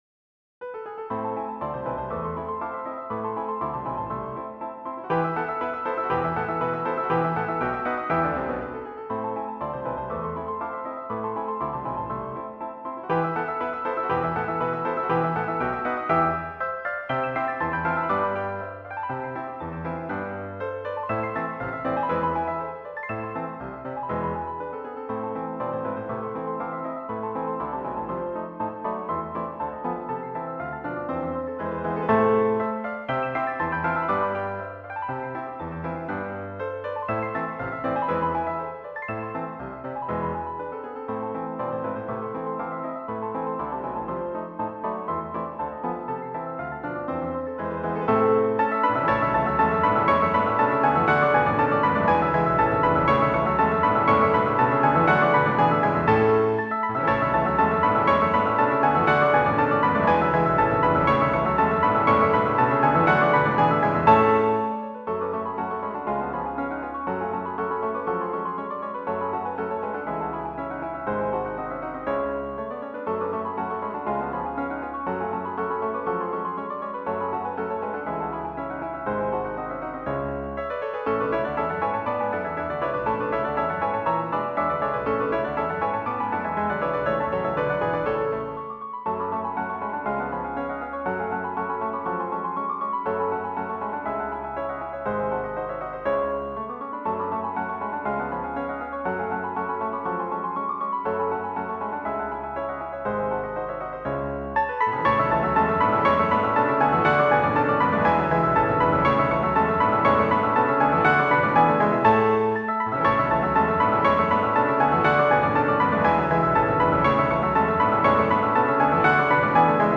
Твори для двох фортепіано у чотири та вісім рук